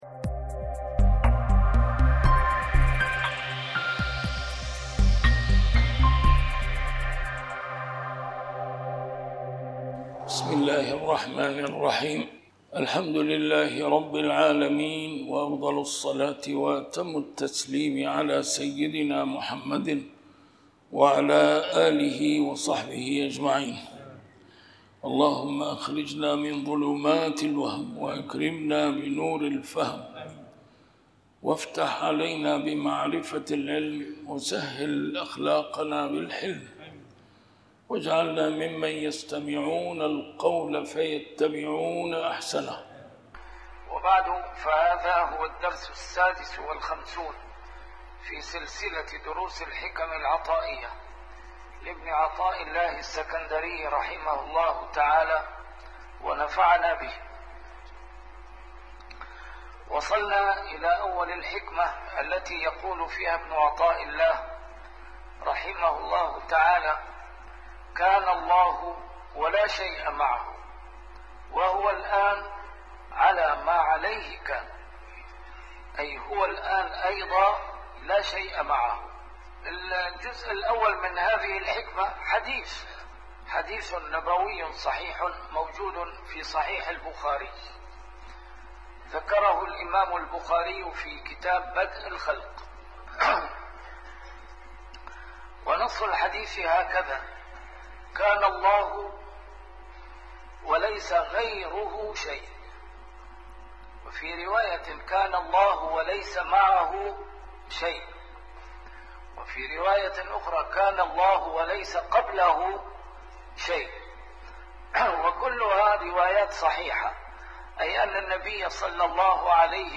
A MARTYR SCHOLAR: IMAM MUHAMMAD SAEED RAMADAN AL-BOUTI - الدروس العلمية - شرح الحكم العطائية - الدرس رقم 56 شرح الحكمة 37